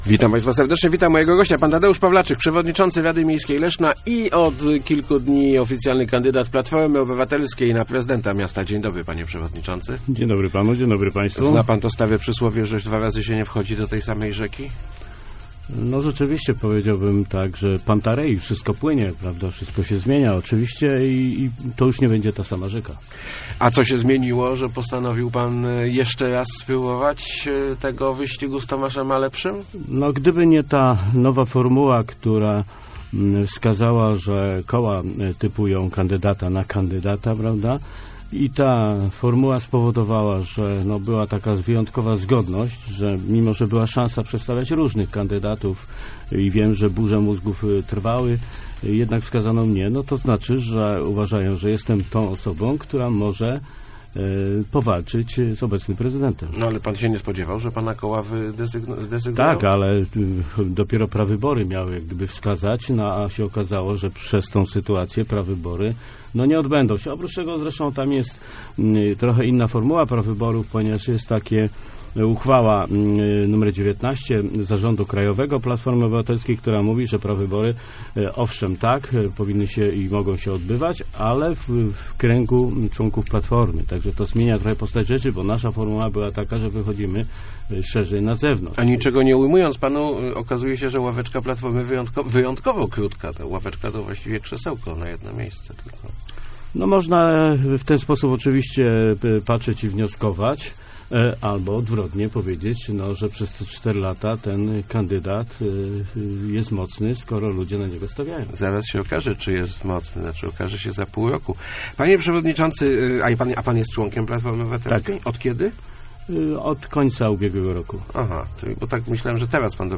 Przez te cztery lata wiele się nauczyłem - mówił w Rozmowach Elki Tadeusz Pawlaczyk, przewodniczący Rady Miejskiej Leszna, kandydat PO na prezydenta. Przyznał, że w poprzedniej kampanii popełniono sporo błędów, które tym razem będą wyeliminowane.